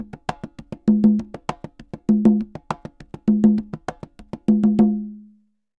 Congas_Salsa 100_3.wav